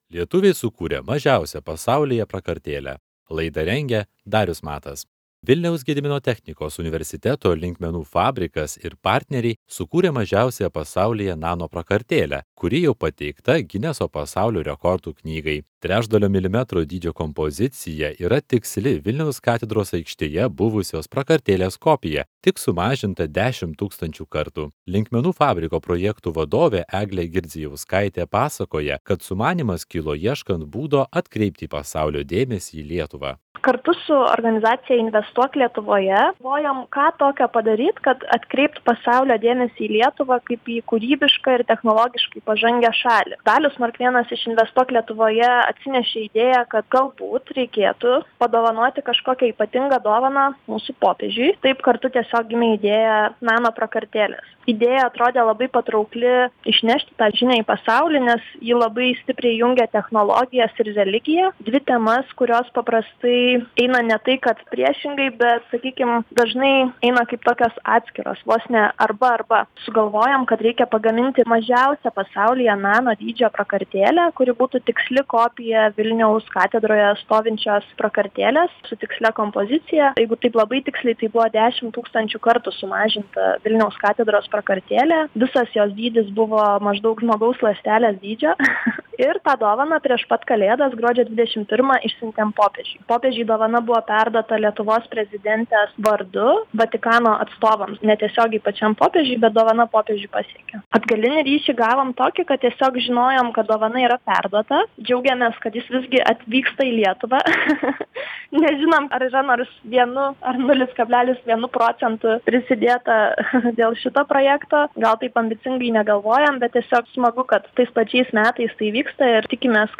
Pokalbis